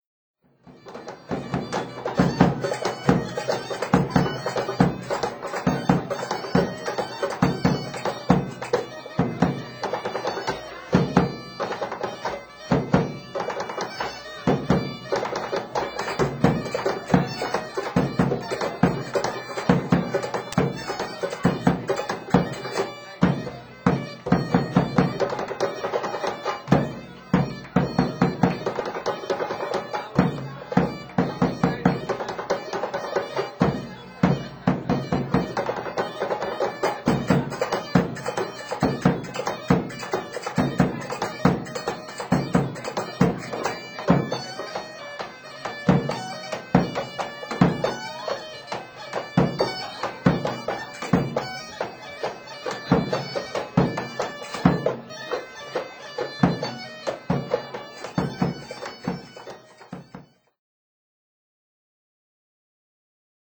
Koutsouki Traditional Gainta ( ηχητικό απόσπασμα – Παραδοσιακή Γκάιντα και Νταούλια )
Ο κοινός παρονομαστής ειναι το ακαταμάχητο Groove που μετατρέπει κάθε συναυλία τους σε ξέφρενη χορευτική βραδιά.